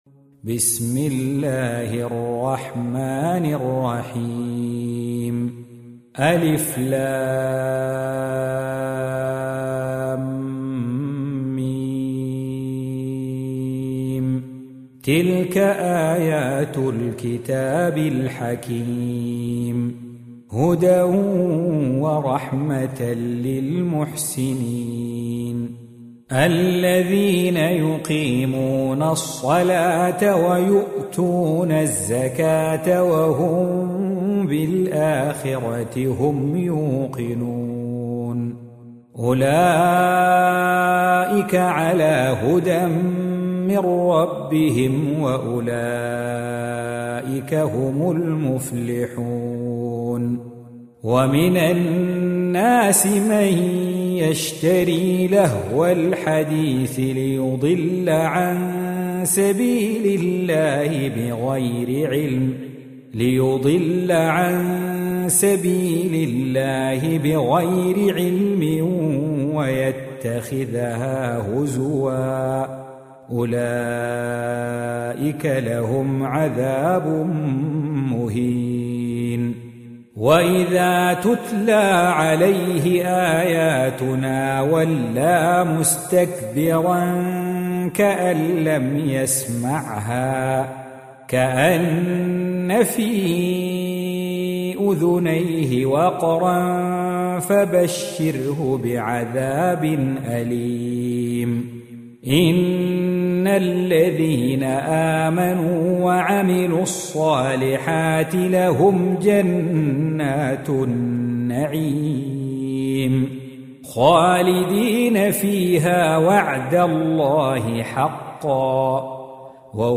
31. Surah Luqm�n سورة لقمان Audio Quran Tarteel Recitation
حفص عن عاصم Hafs for Assem